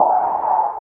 51 WRD CYM-R.wav